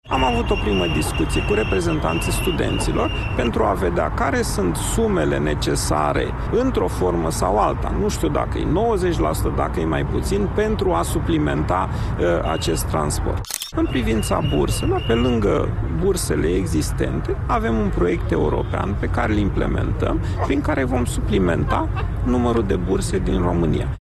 La protestul de la Guvern a mers și noul ministru al Educației, Mihai Dimian, pentru a discuta cu tinerii, declarații transmise de TVR. A spus că se caută soluții de a folosi bani europeni pentru acoperirea unor goluri din Educație.